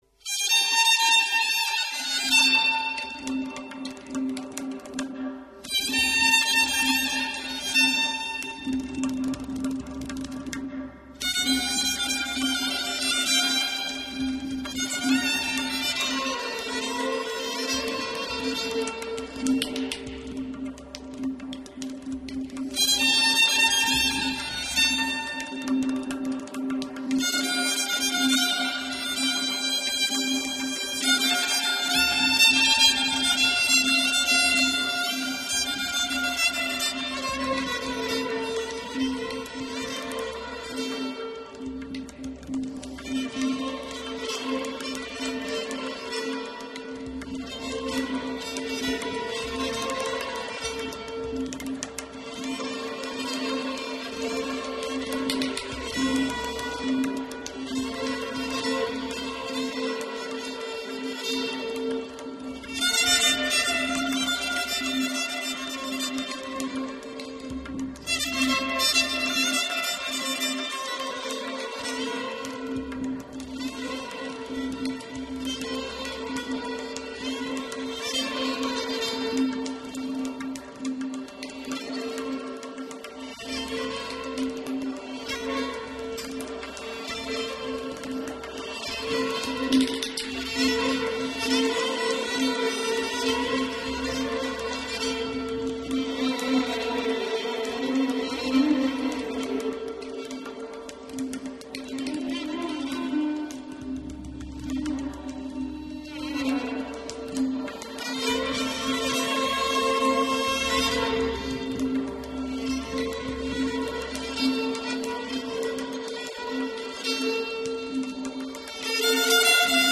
خوانندهٔ موسیقی اصیل ایرانی است .
۰۲- آهنگ ساز و آواز